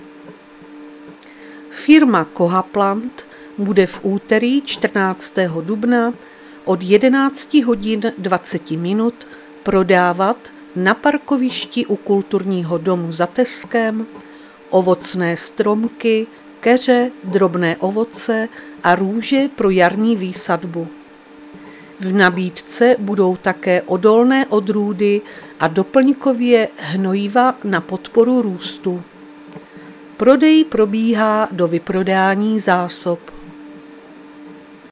Záznam hlášení místního rozhlasu 13.4.2026